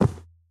Minecraft Version Minecraft Version snapshot Latest Release | Latest Snapshot snapshot / assets / minecraft / sounds / mob / camel / step1.ogg Compare With Compare With Latest Release | Latest Snapshot
step1.ogg